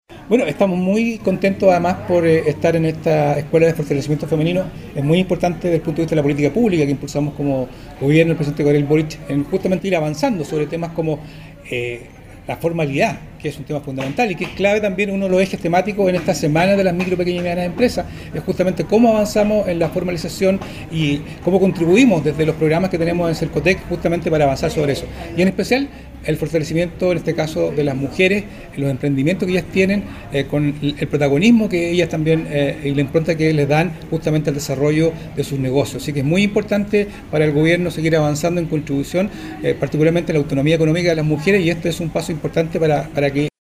La actividad se realizó junto a productoras y cultoras de la región que dieron vida a una nueva versión del tradicional “Mercado Campesino” de Indap, en instalaciones del Parque Costanera de Puerto Montt; en el marco de la Semana de las Mipymes y Cooperativas 2024 que desarrolló la Seremía de Economía y sus servicios dependientes.
Por su parte, el Seremi de Economía Luis Cárdenas, indicó que vienen a reforzar instancias de fomento que son muy necesarias para los emprendedores, en este caso, del mundo rural los cuales se han destacado en especial la presente semana.